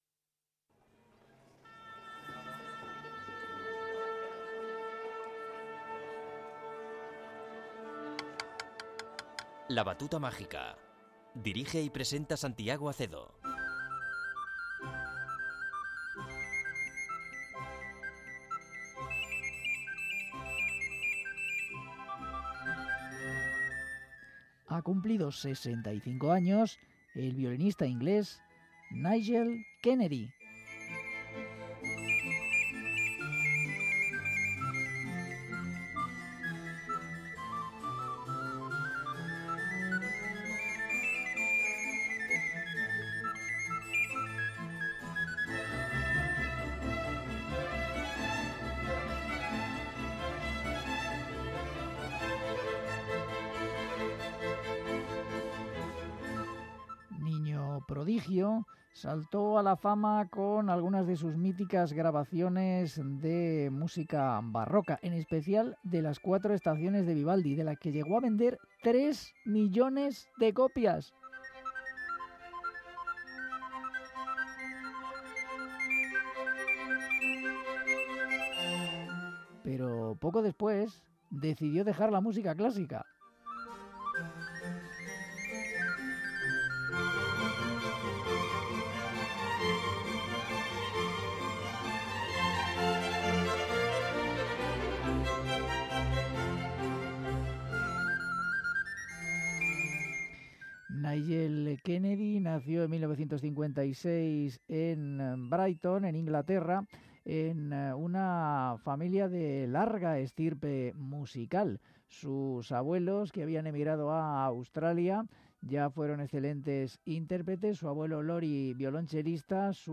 violinista